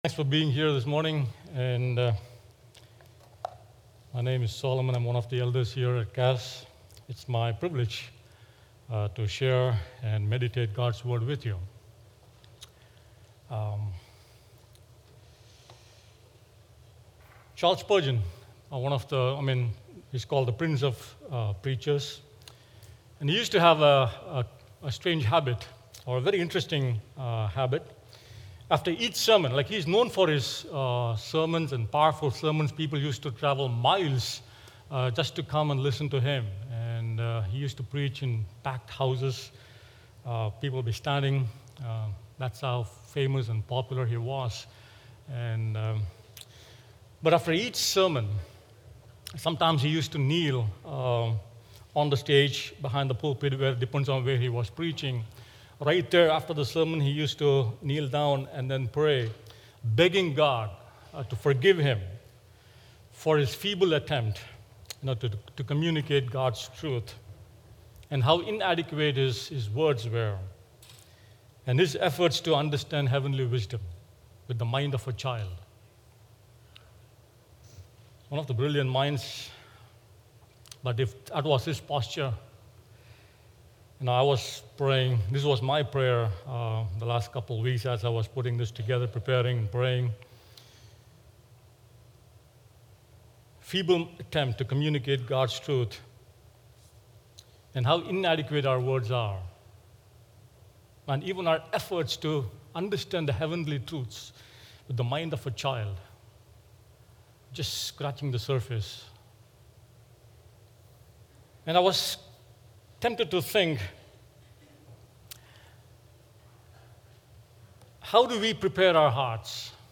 A sermon on 2 Corinthians 4:1–4 highlighting the power of the gospel, the reality of spiritual blindness, and the call to faithful proclamation.